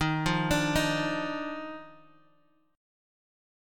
EbM7sus2 chord